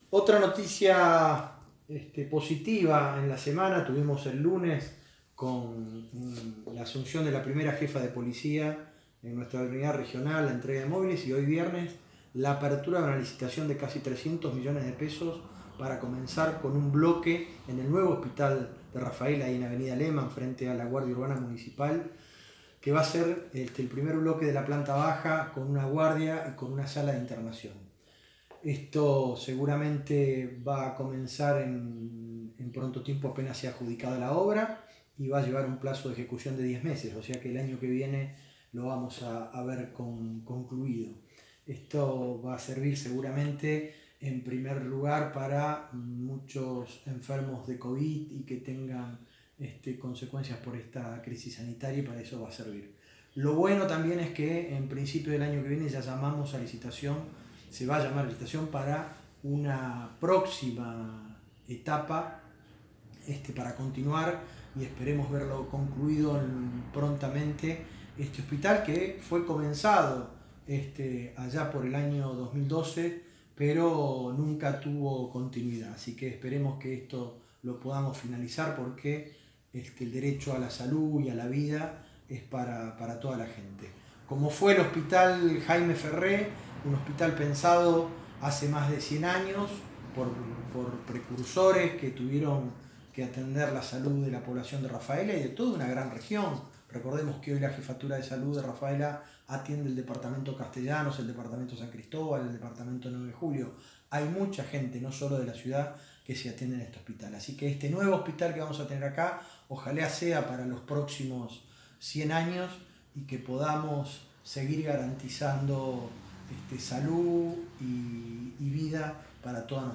Senador Nacional Roberto Mirabella